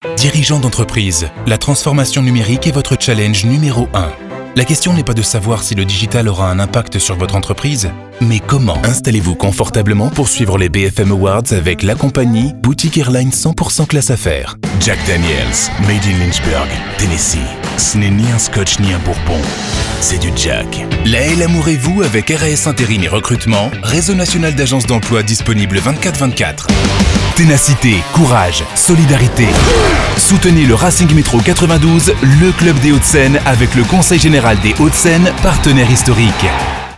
Commercieel, Natuurlijk, Veelzijdig, Vriendelijk, Zakelijk
Commercieel